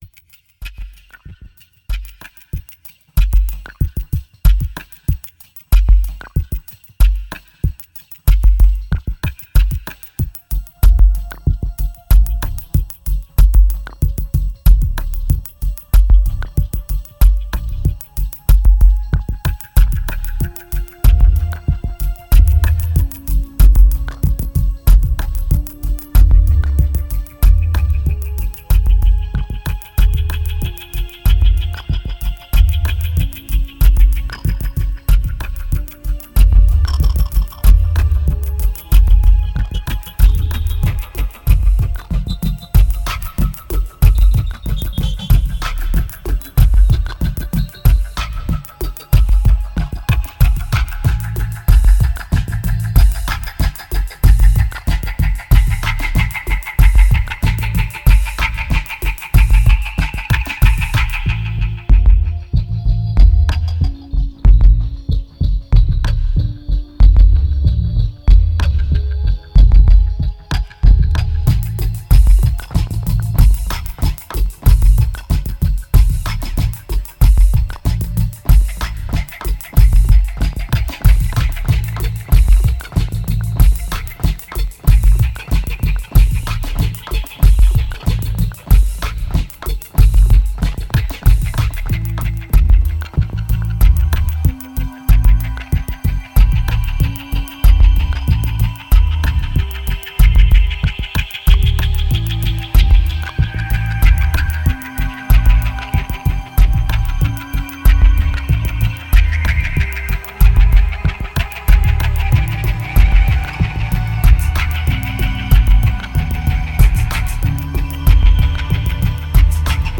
Tense and diverse